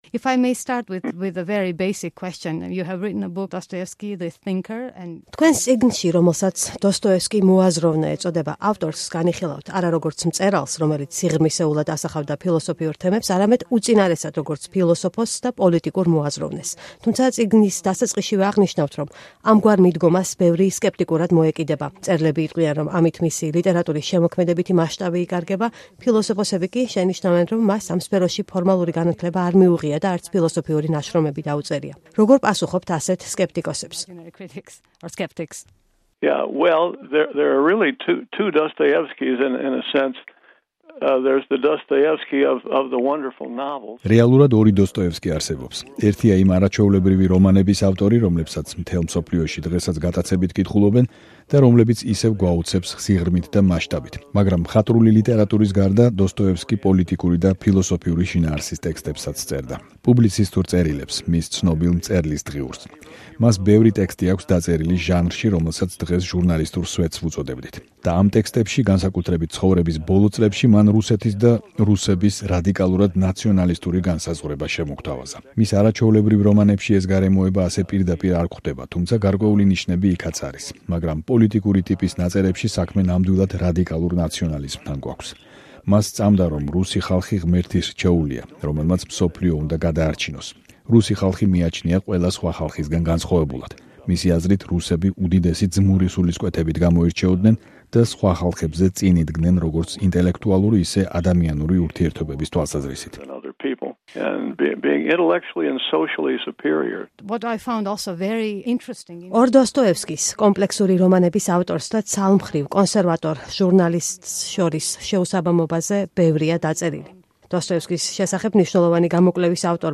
დოსტოევსკი და „რუსული იდეა“: ინტერვიუ მკვლევართან